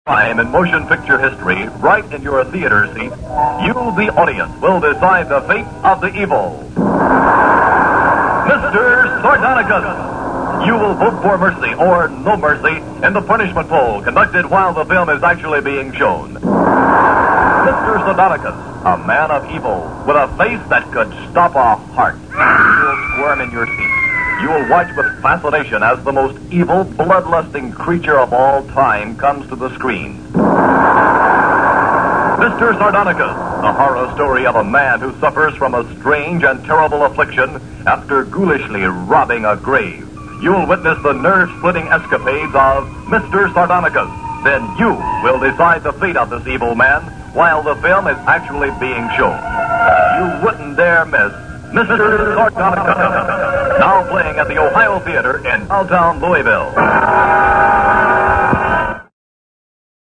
Radio spot for Mr. Sardonicus.
mrsardonicusradiopromo.mp3